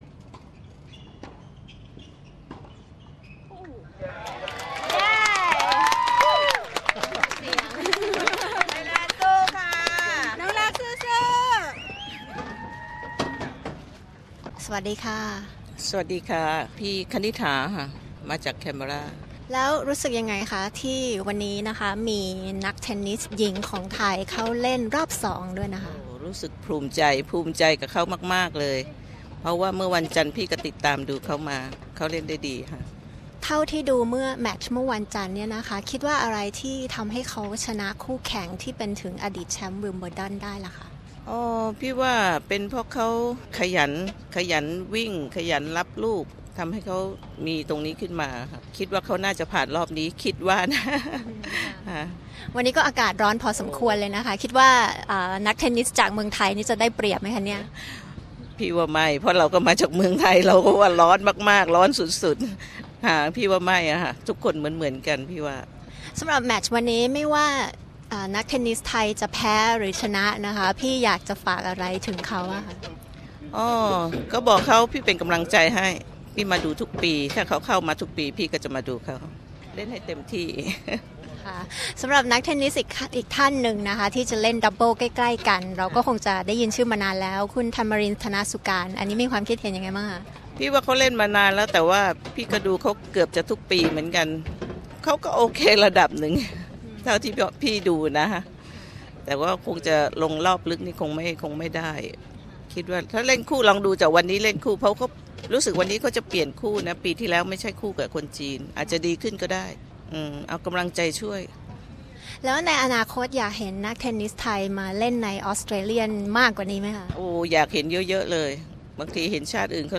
Exclusive Interview with Kumkhum Dumping Kvitova in Aus Open
SBS Thai talks to Luksika Kumkhum, a Thai female tennis player, who was the underdog but beat the number 6th seed and former Wimbledon champion, Petra Kvitova, in the first round of the Australian Open 2014. She tells us exclusively what makes her tick. We also chat to Thai fans and some Aussies who cheer on Luksika from the sides of the court.